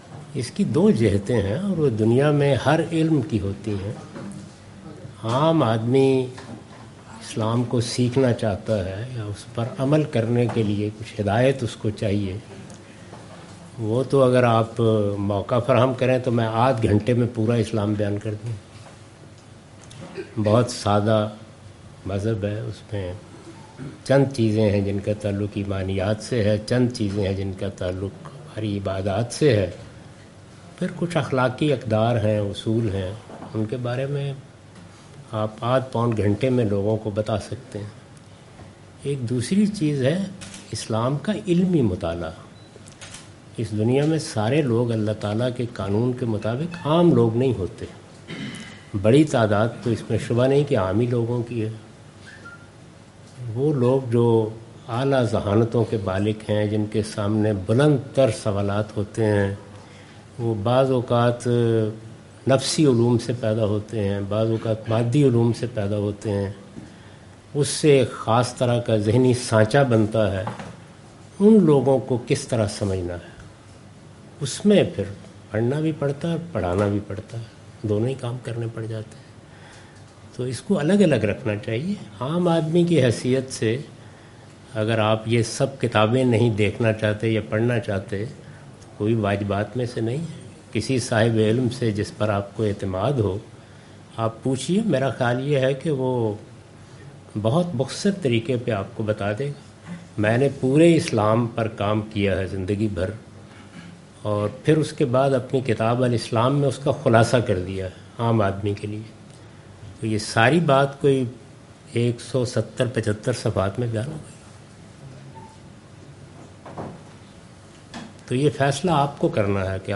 Javed Ahmad Ghamidi answer the question about different topics During his Pakistan visit in Al-Mawrid office Lahore on May 11,2022.